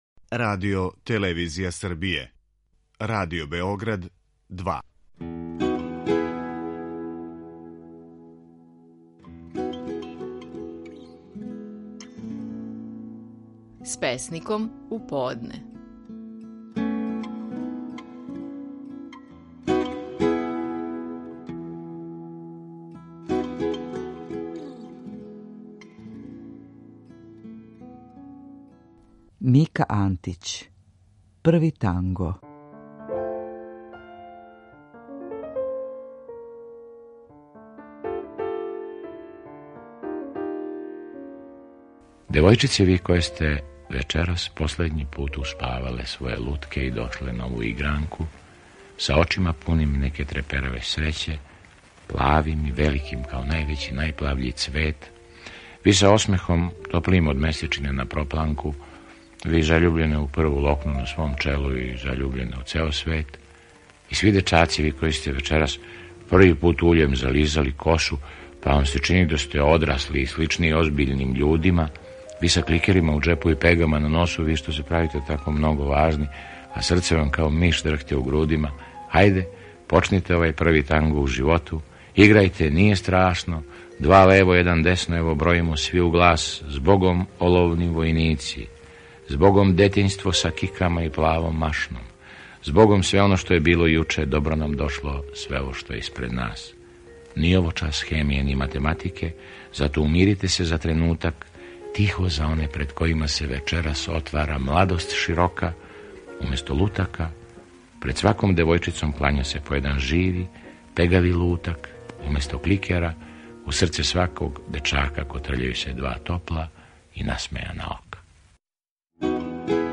Стихови наших најпознатијих песника, у интерпретацији аутора.
Слушамо Мирослава Антића и његову чувену песму „Први танго".